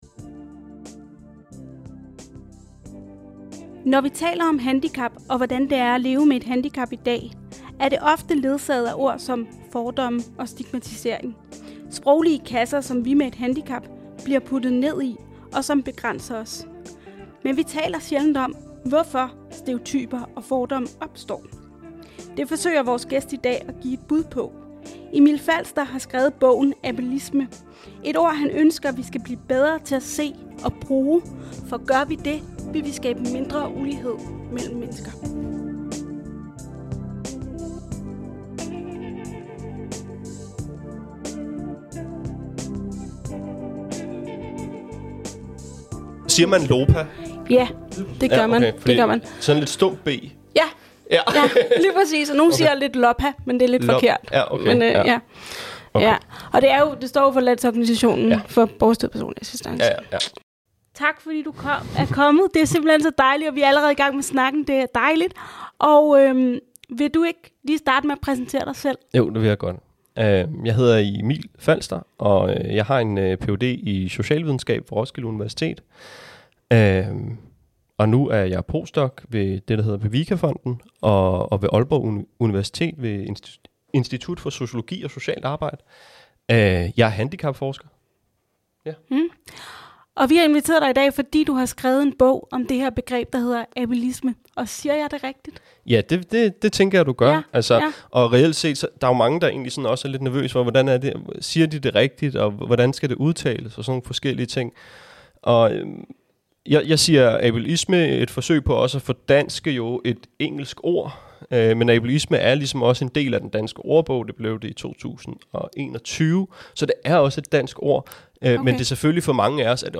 De spørgsmål og mange flere søger vi svar på gennem samtaler med arbejdsledere, arbejdsgivere givere og faglige eksperter.